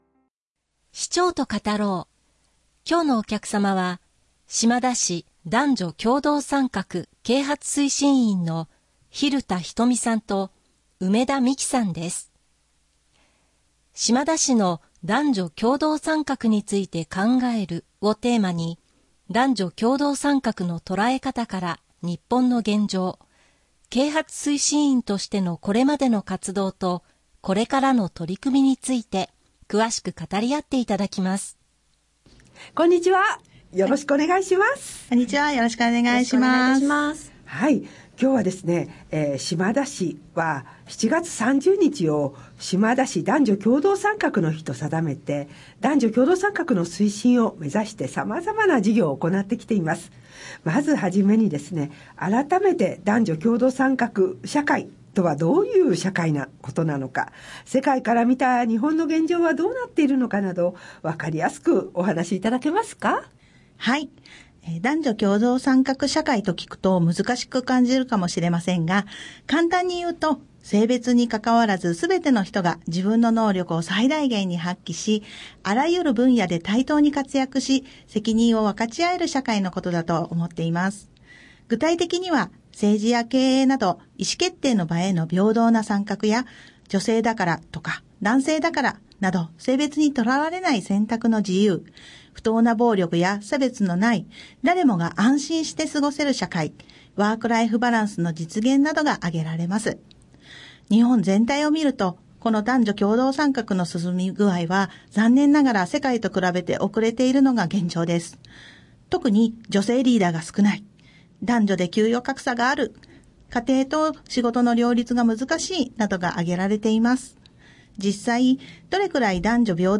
毎月1回、市民をお招きし、注目度や関心度の高い話題をテーマに、市長と対談形式でラジオ放送をお送りします。